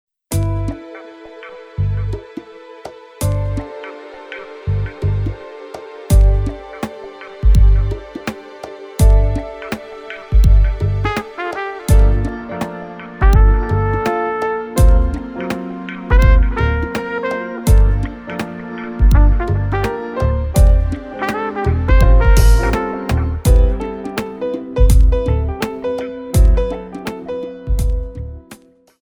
Alto sax.